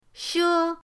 she1.mp3